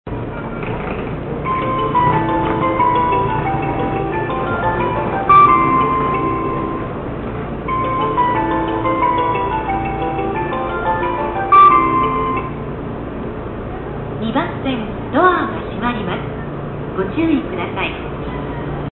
甲府駅２番線 中央本線の甲府駅のメロディ＆発車放送です。